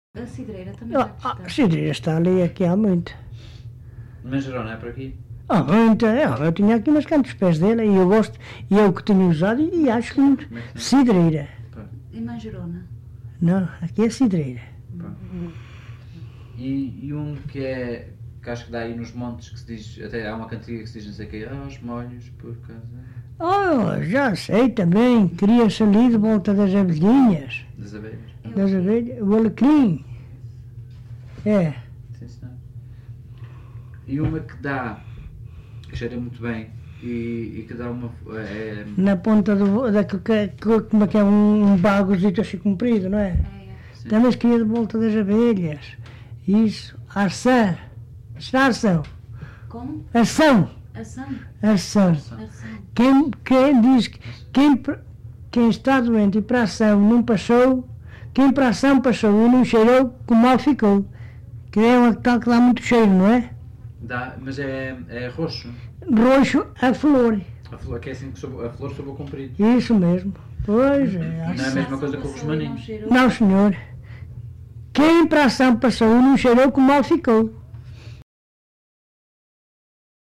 LocalidadeSanto André (Montalegre, Vila Real)